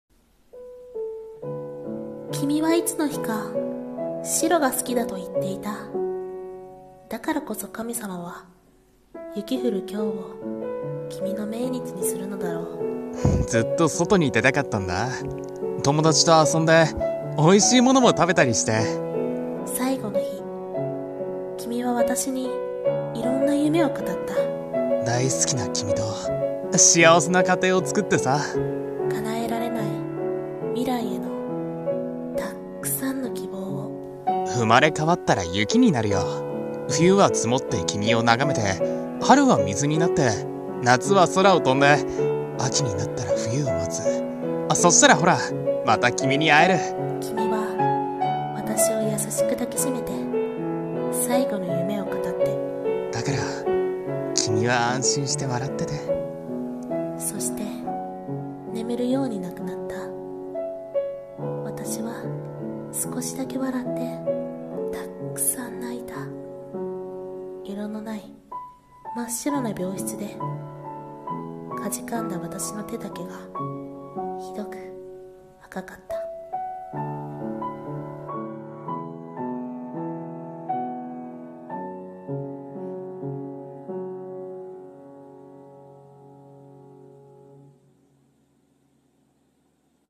【二人声劇】君は白に愛された。